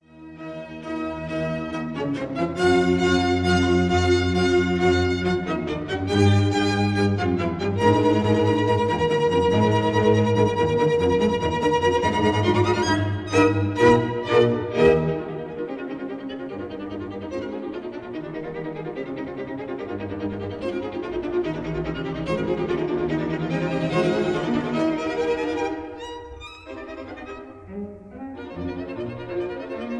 violins
violas
cellos